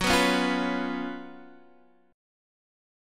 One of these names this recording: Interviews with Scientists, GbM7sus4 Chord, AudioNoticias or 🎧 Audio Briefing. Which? GbM7sus4 Chord